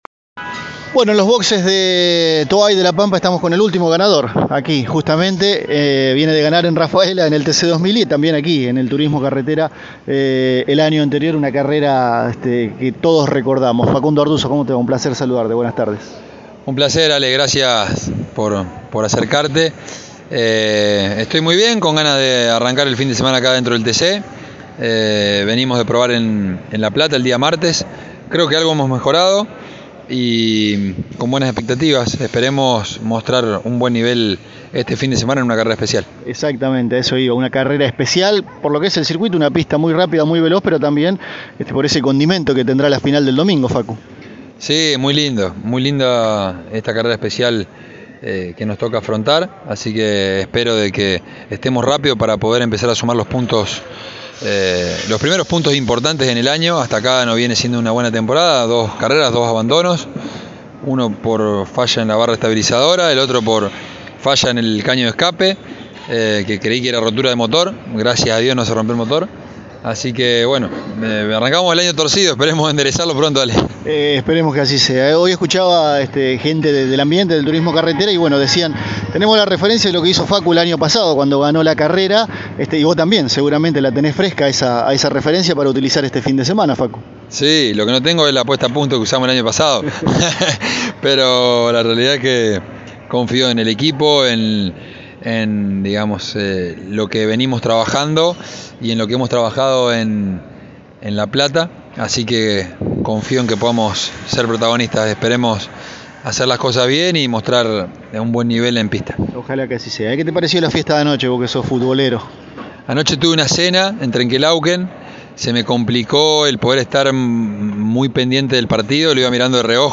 El piloto de Las Parejas pasó los micrófonos de Pole Position y habló de las expectativas de correr nuevamente en La Pampa, donde consiguió el triunfo en la última competencia disputadas allí.